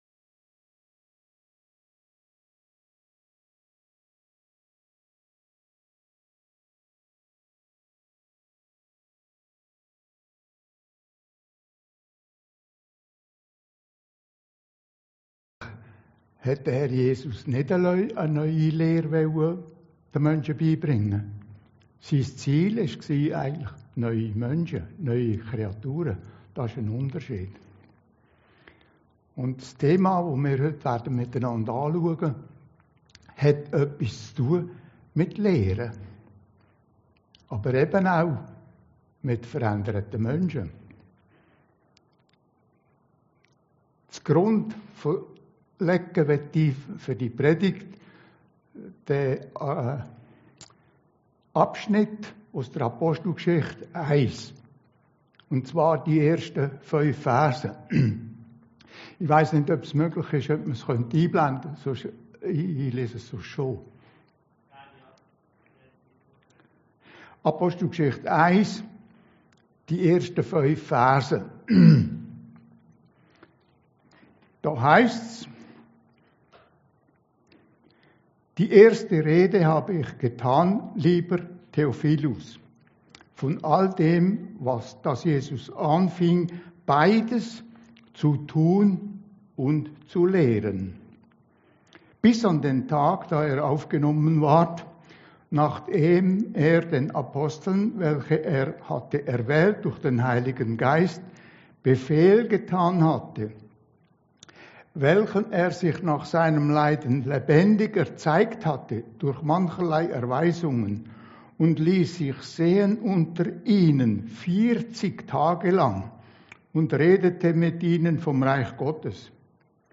Predigten Heilsarmee Aargau Süd – Jesus lehrt seine Jünger